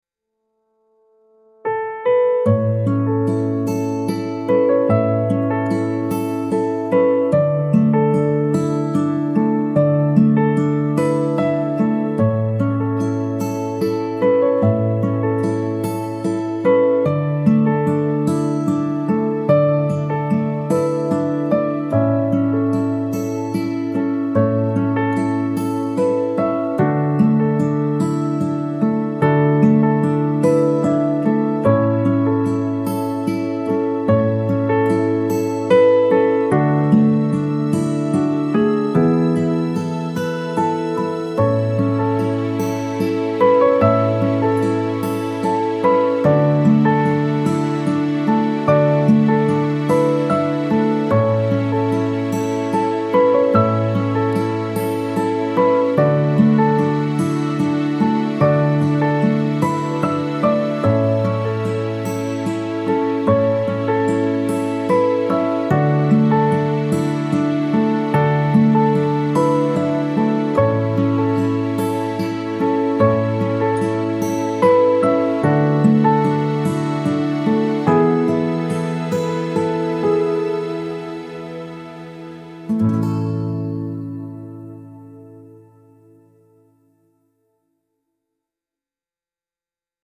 warm heartfelt family music with acoustic guitar, piano and soft strings